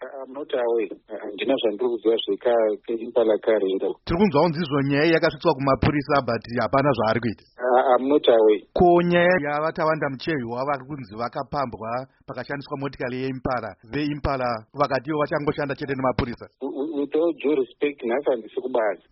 Hurukuro naVaPaul Nyathi